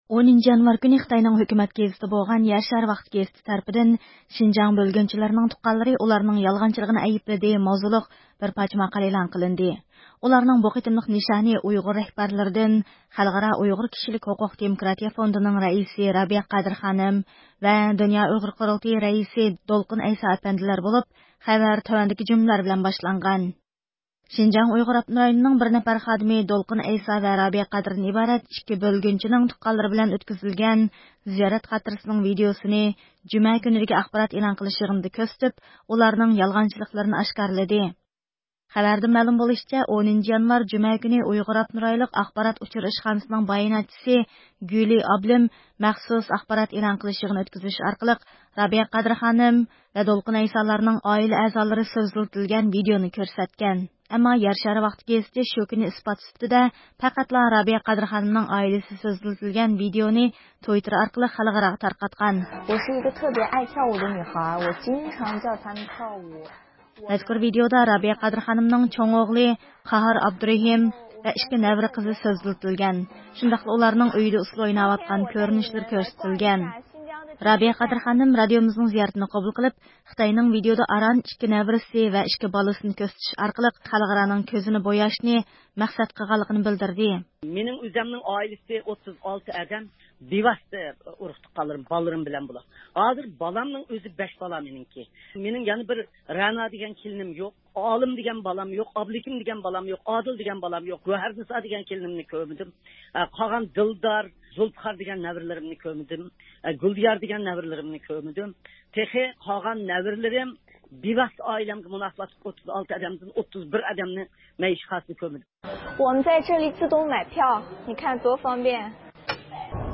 رابىيە قادىر خانىم رادىيومىزنىڭ زىيارىتىنى قوبۇل قىلىپ، خىتاينىڭ ۋىدىيودا ئاران ئىككى نەۋرىسى ۋە ئىككى بالىسىنى كۆرسىتىش ئارقىلىق ئامېرىكا ھۆكۈمىتى ۋە خەلقئارانىڭ كۆزىنى بوياشنى مەقسەت قىلغانلىقىنى بىلدۈردى.